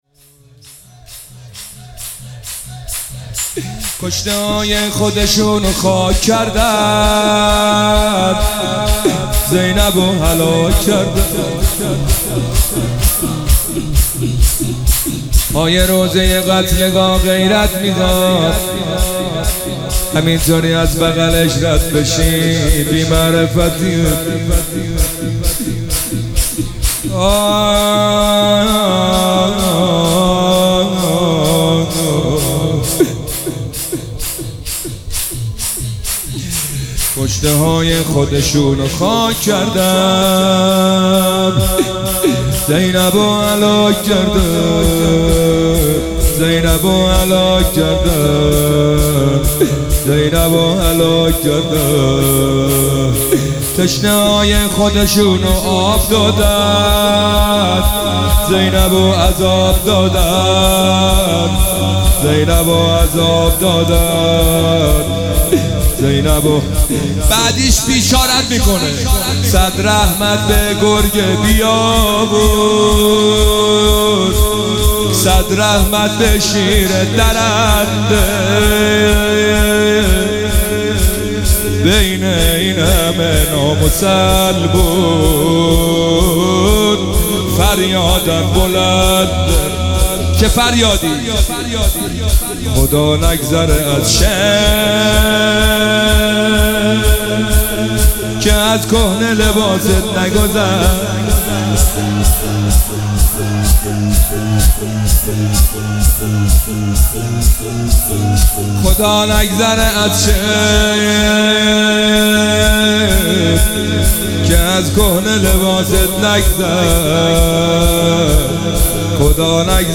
مداحی شور روضه ای شب دوم محرم 1404